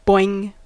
Ääntäminen
Synonyymit doing Ääntäminen : IPA : /bɔ.ɪŋ/ US : IPA : [bɔ.ɪŋ] Haettu sana löytyi näillä lähdekielillä: englanti Käännöksiä ei löytynyt valitulle kohdekielelle.